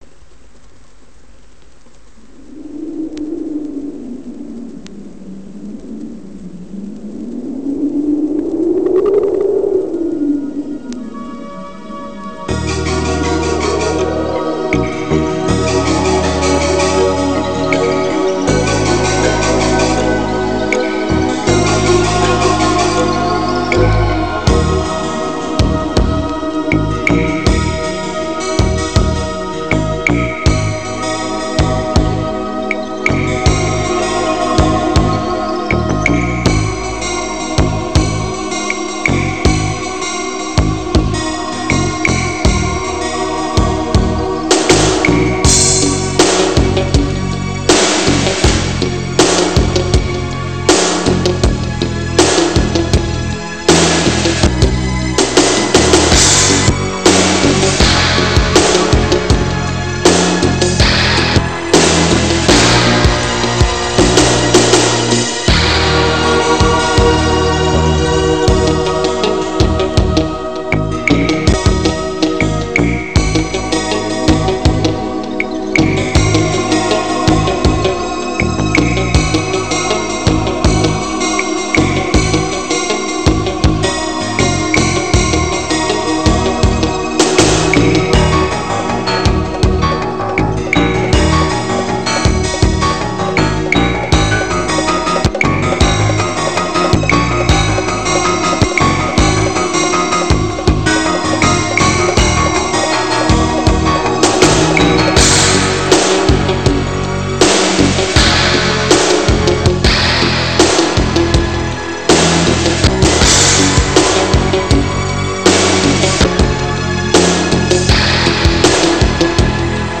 Musica strumentale
Genere: Pop
Stile: Pianobar alla francese